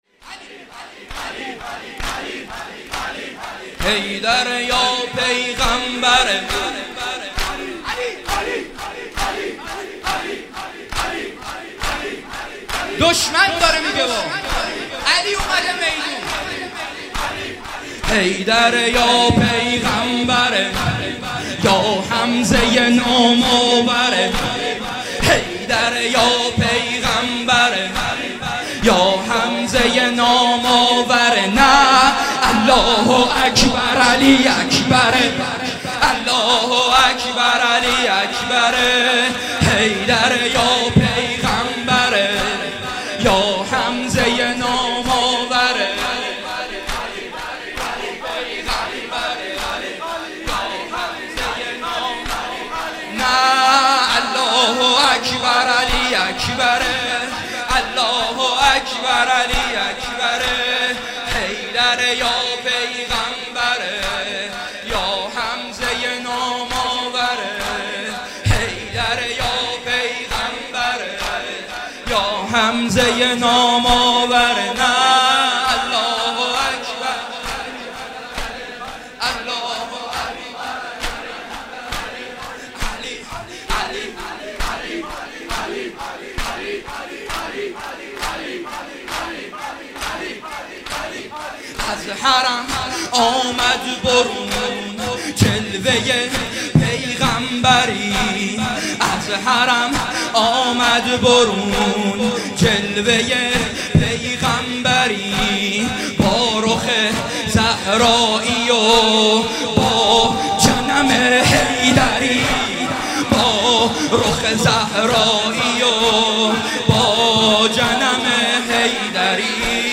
زمینه (حیدره یا پیغمبره، یا حمزه ی نام آوره...)
همه چی عالیه فقط یه انتقاد کوچولو : چرا کیفیت مداحیا ایقد پایینه ؟؟؟؟؟؟؟؟؟؟؟؟؟؟؟